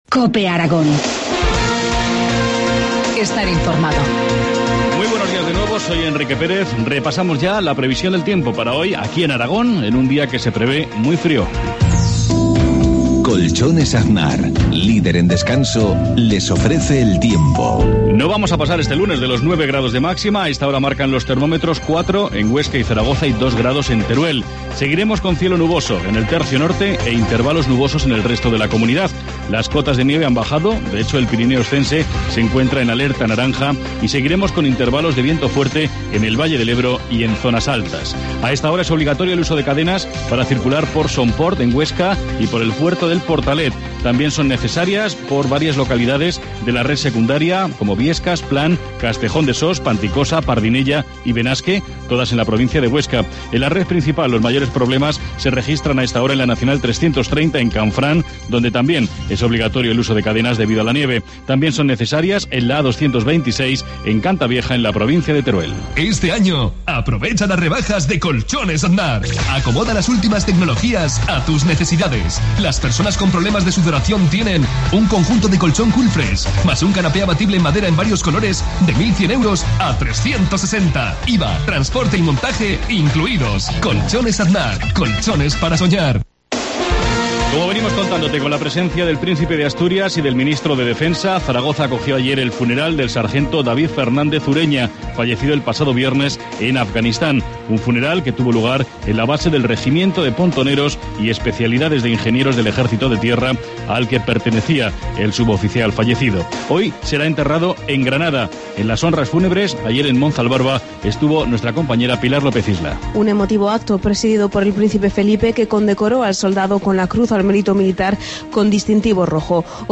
Informativo matinal, lunes 14 de enero, 7.53 horas